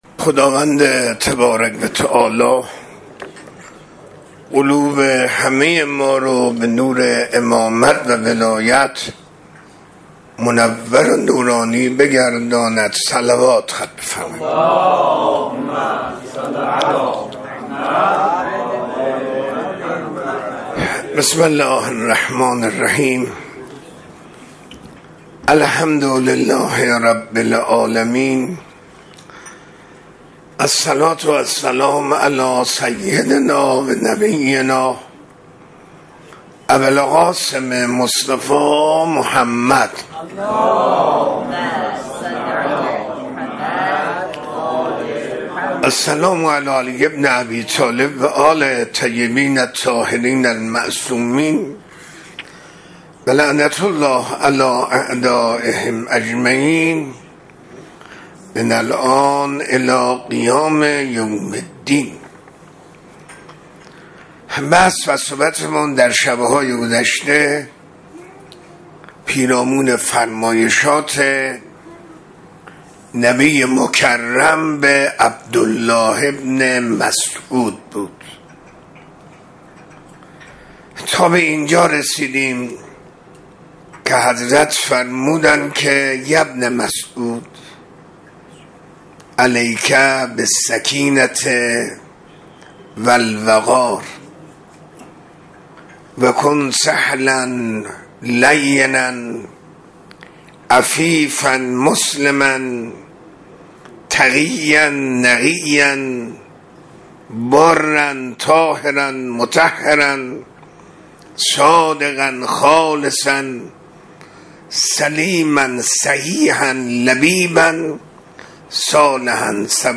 منبر